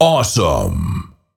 Фразы после убийства противника
Awesome.mp3